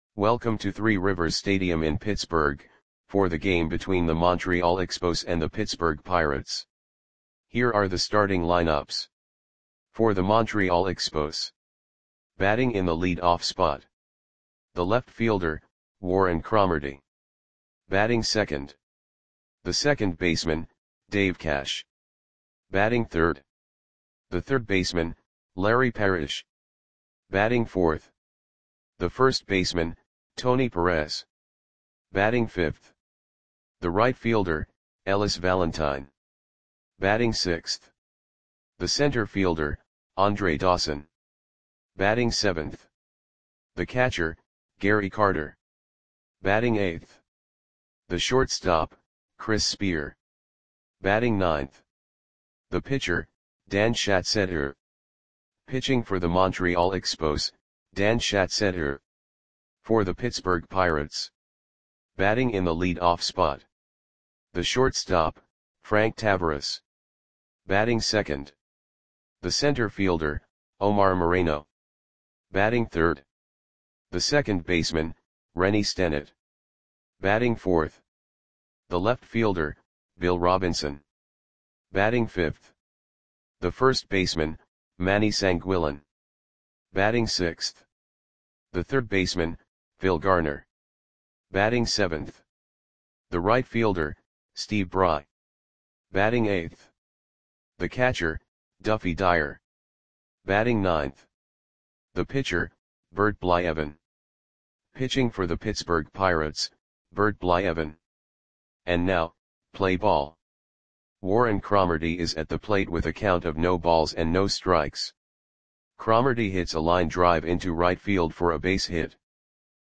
Audio Play-by-Play for Pittsburgh Pirates on July 4, 1978
Click the button below to listen to the audio play-by-play.